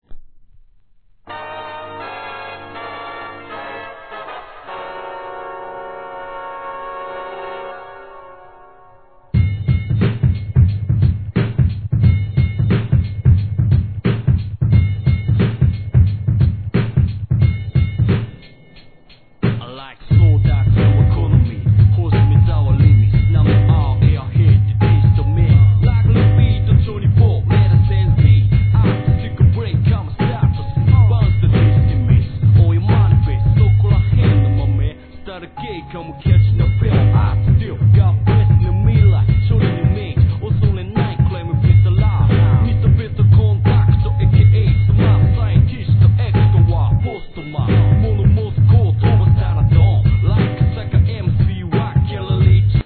JAPANESE HIP HOP/R&B
嫌でも流れる時代に、愛すべき不変のHIP HOPと日本語と英語を絶妙に織り交ぜるオリジナルスタイルがある!!